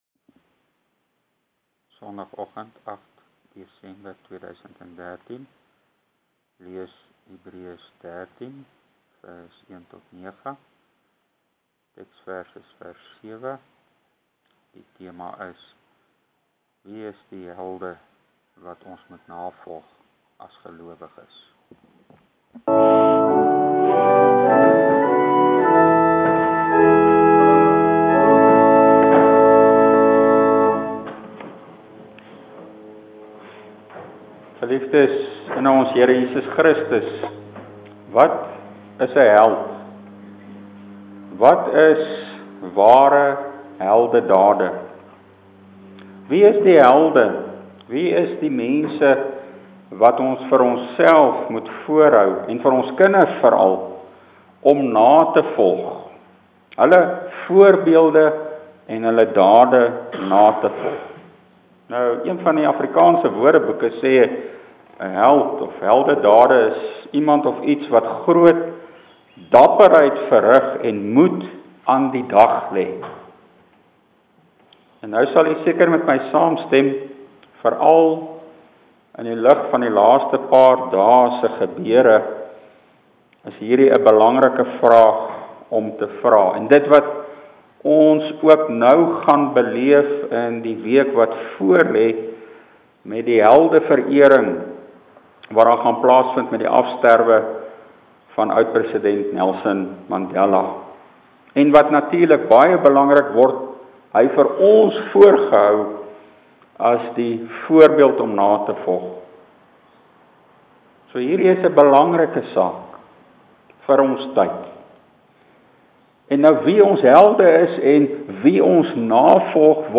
Preek: Hebr.13:7 – Vertrou nie op mensehelde, maar op Christus, die volmaakte Held